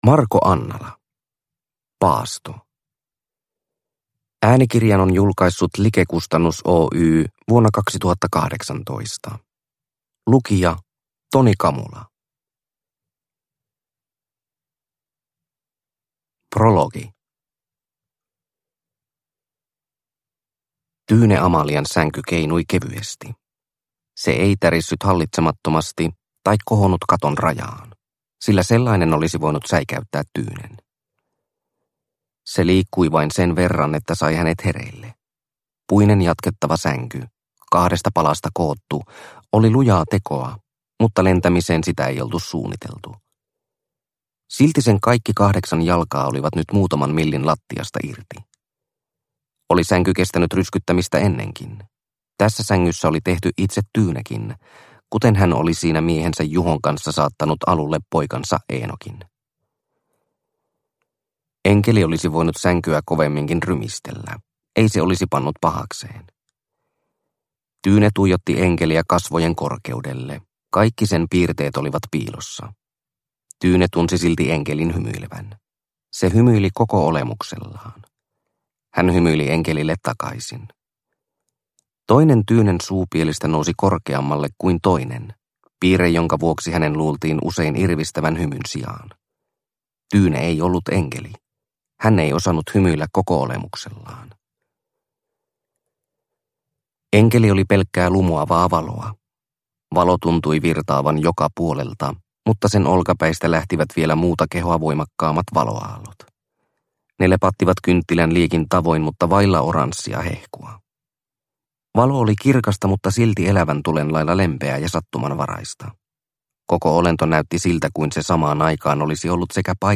Paasto – Ljudbok – Laddas ner